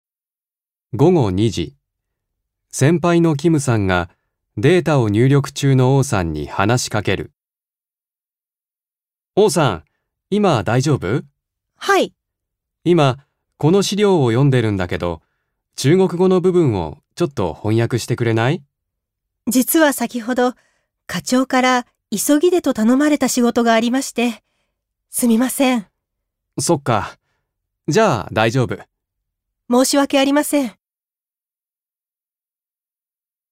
1. 会話